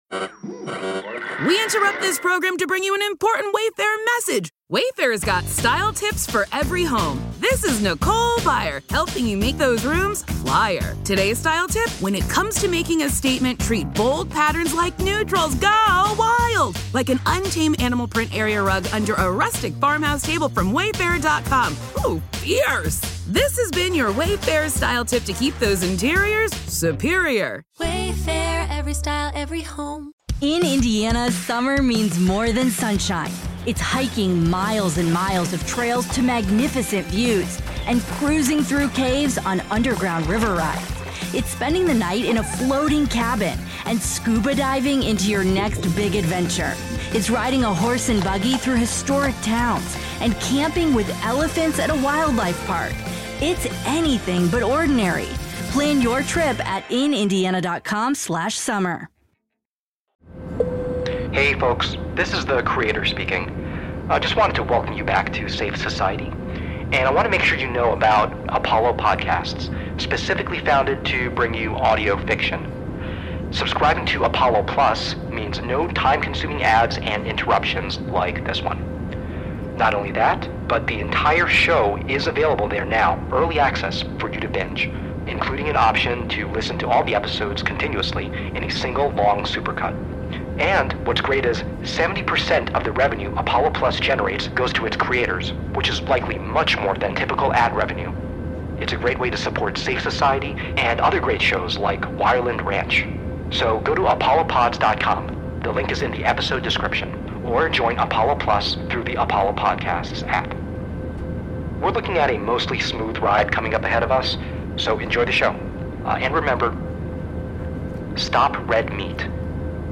Library of Audio Fiction Podcasts
Moderate language, moments of unsettling sound design and voices, sudden violence, death, and suffering, allusions to neurosis, brainwashing, and cult rituals